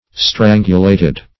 Strangulated \Stran"gu*la`ted\, a.